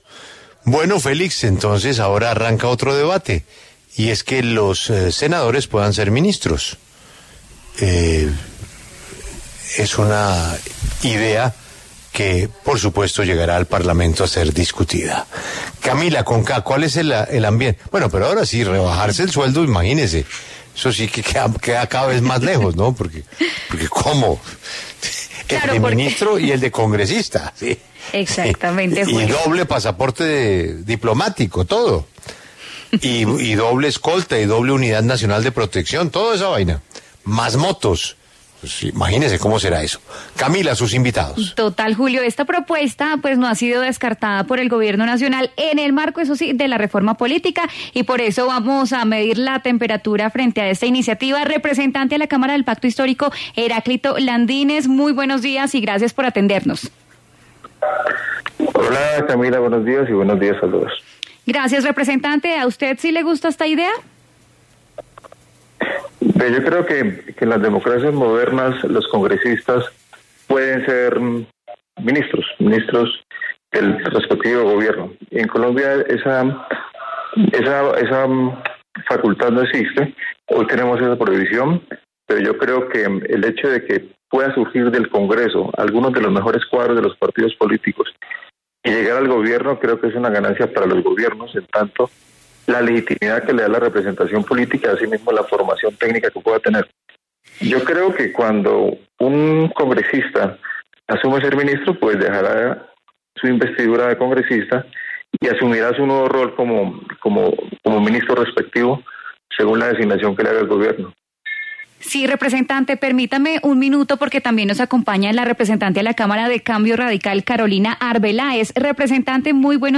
Escuche la entrevista a Heráclito Landínez y Carolina Arbeláez en La W: